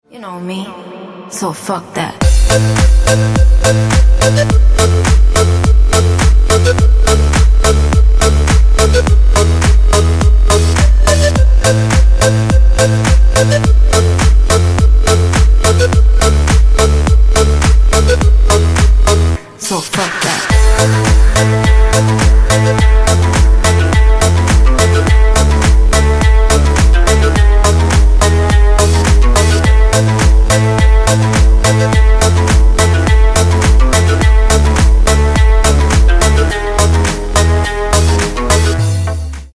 Громкие Рингтоны С Басами
Танцевальные Рингтоны